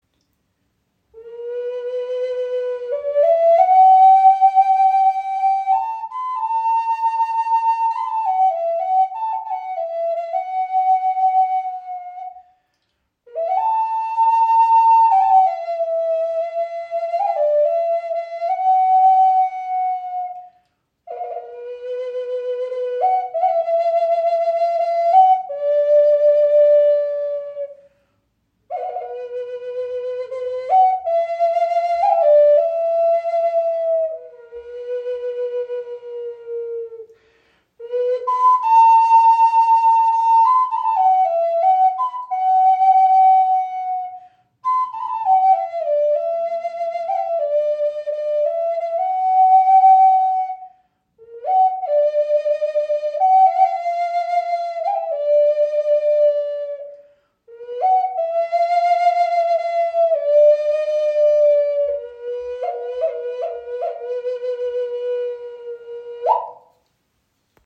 Okarina aus einem Aststück | C5 in 432 Hz | Pentatonische Stimmung | ca. 17 cm
Handgefertigte 5 Loch Okarina aus Teakholz – pentatonische C5 Moll Stimmung in 432 Hz, warmer klarer Klang, jedes Stück ein Unikat.
In pentatonischer C5 Moll Stimmung auf 432 Hz gestimmt, entfaltet sie einen warmen, klaren Klang, der Herz und Seele berührt.
Trotz ihrer handlichen Grösse erzeugt sie einen angenehm tiefen und warmen Klang – fast ebenbürtig zur nordamerikanischen Gebetsflöte.